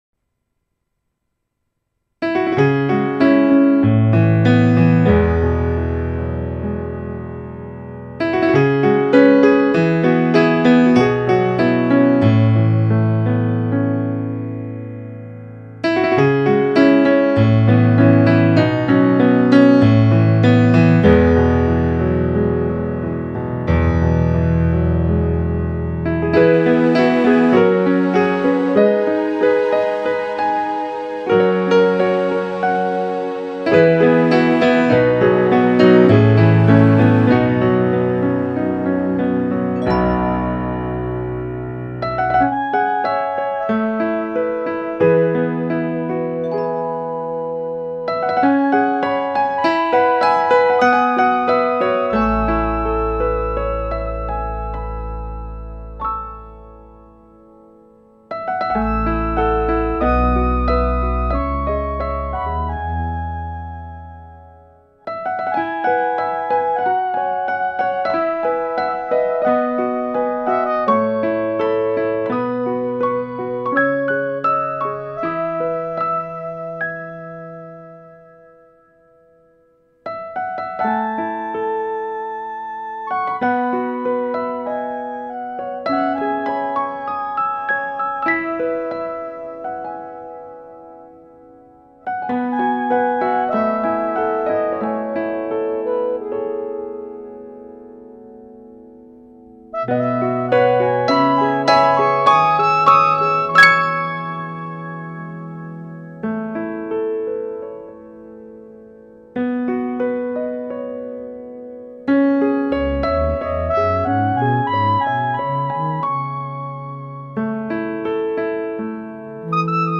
Key: C# Minor Tempo: No consistent tempo ---